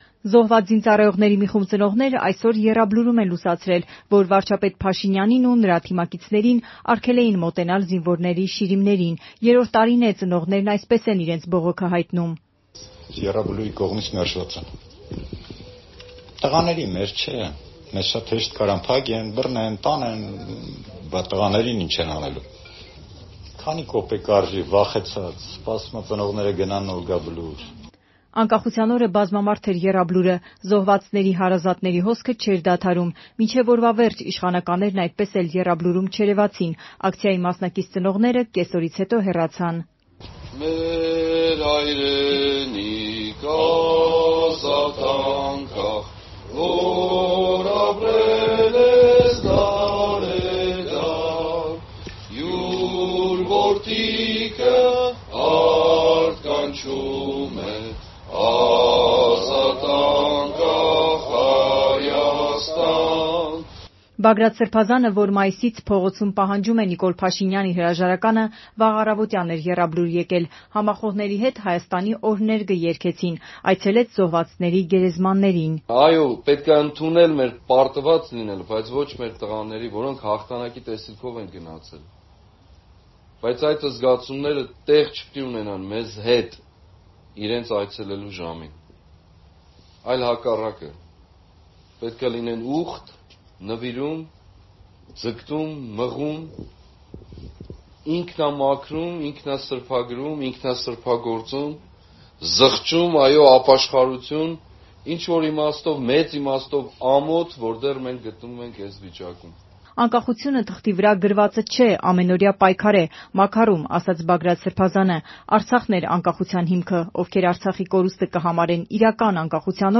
Փաշինյանի տոնական ուղերձը